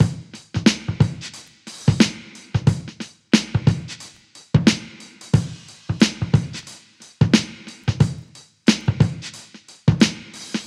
• 90 Bpm Breakbeat E Key.wav
Free drum beat - kick tuned to the E note. Loudest frequency: 1114Hz
90-bpm-breakbeat-e-key-Fjm.wav